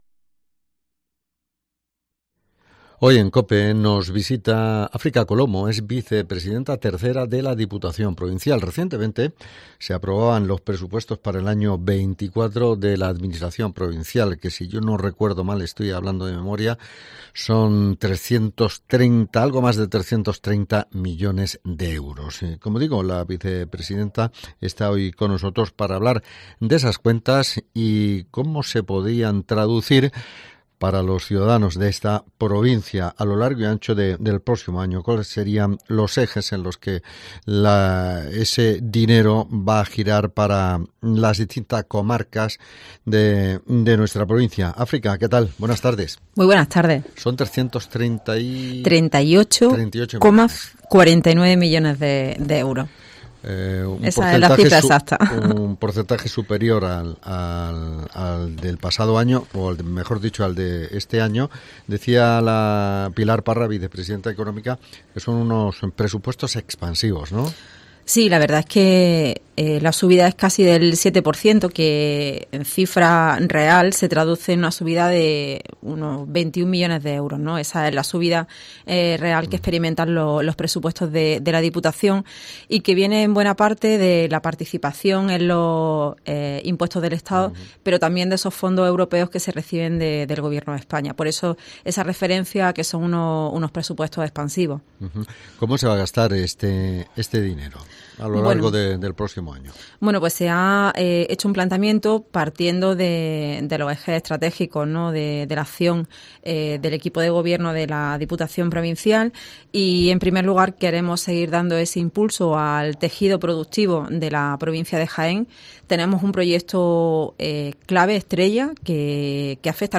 Entrevista con África Colomo